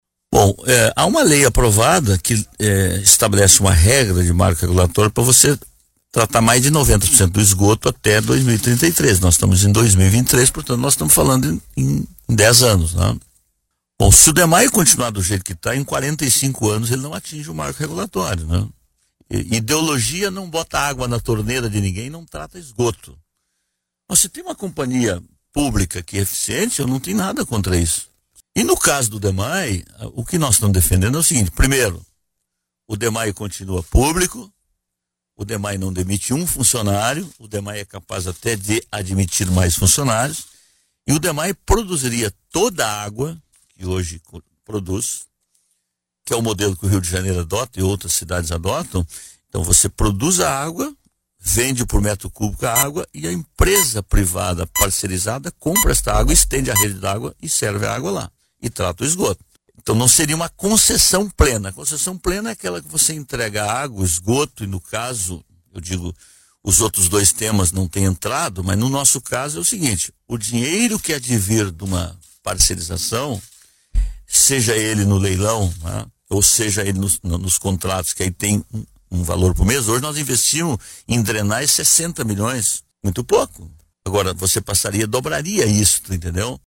Declarações ocorreram no programa Esfera Pública, da Rádio Guaíba
Em entrevista ao programa Esfera Pública, o chefe do Executivo municipal ainda defendeu a extinção da licença-prêmio dos servidores municipais, que deve ser votada até o fim do mês na Câmara de Vereadores.